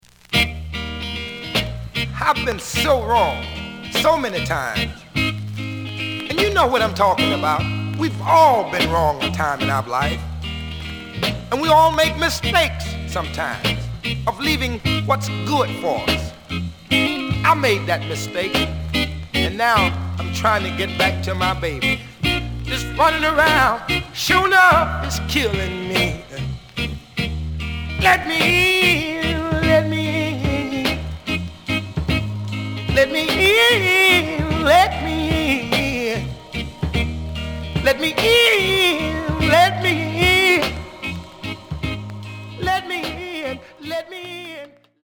The audio sample is recorded from the actual item.
●Genre: Soul, 60's Soul
Edge warp.